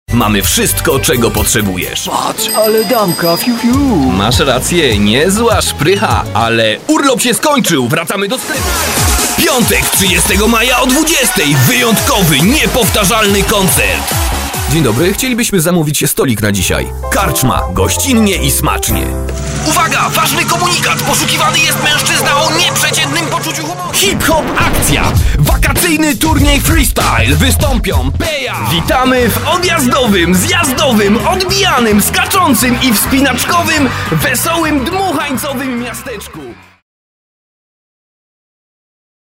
polnischer Sprecher
Kein Dialekt
polish voice over artist